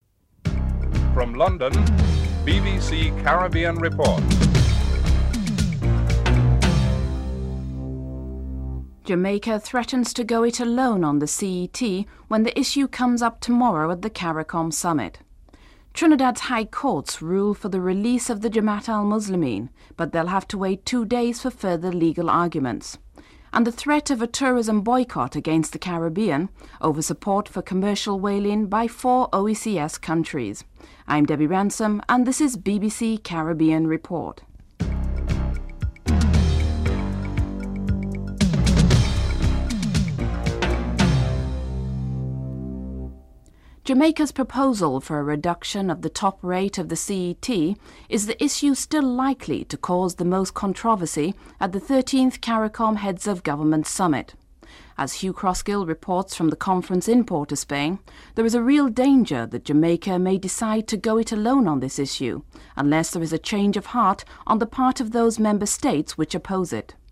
The British Broadcasting Corporation
1. Headlines (00:00-00:44)